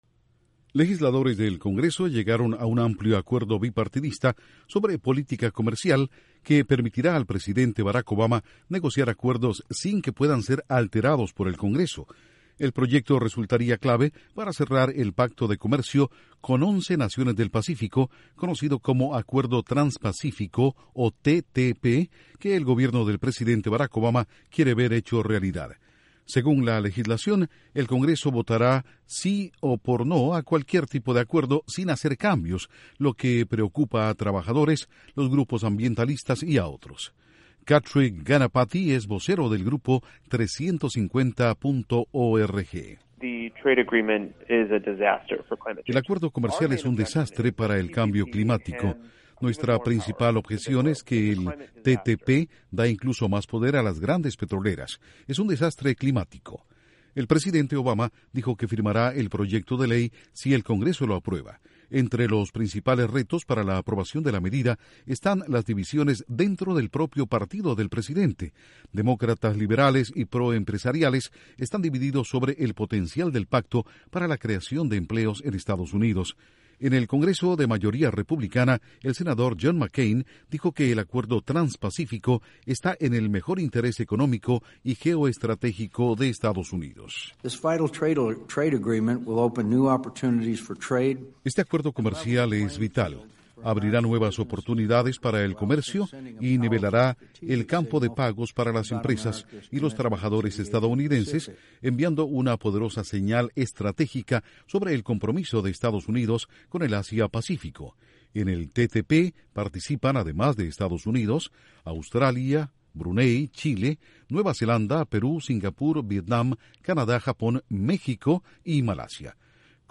Legisladores dan al presidente Obama autoridad de negociación expedita para acuerdos comerciales como Trans-Pacífico.. que entre otros países incluye a Canadá, EEUU, México, Chile y Perú. Informa desde los estudios de la Voz de América en Washington